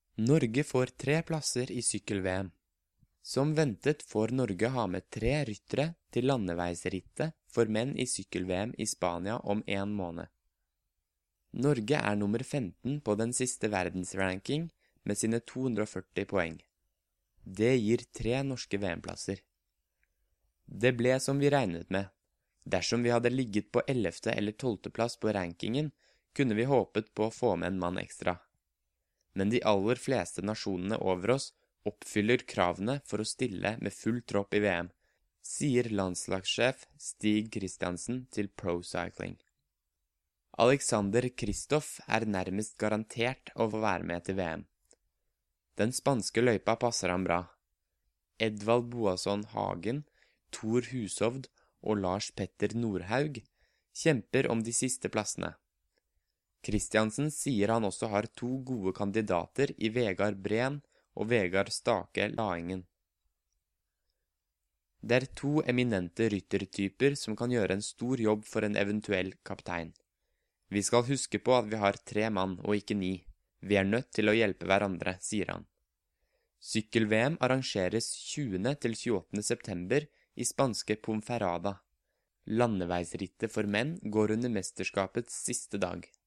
Learn Norwegian by listening to a native Norwegian while you’re reading along.